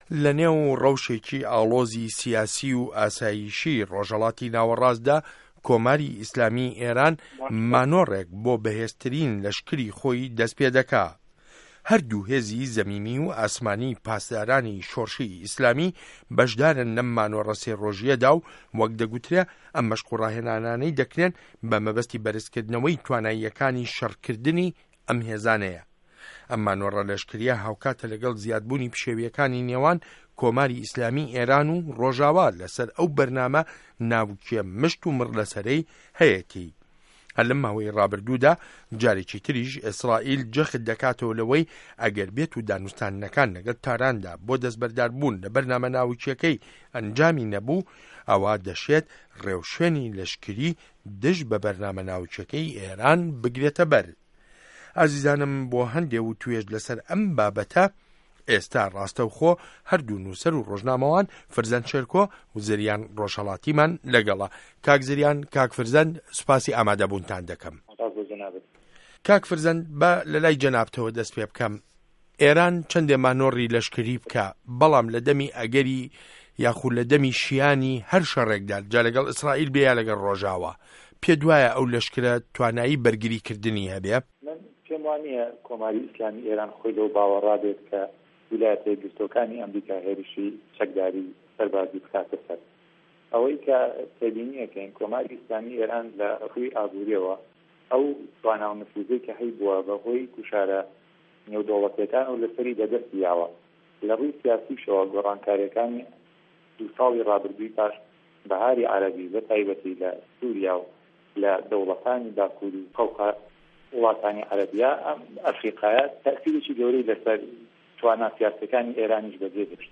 مێزگرد : په‌یامی ئێران له‌ مانۆڕه‌ له‌شکریه‌که‌یدا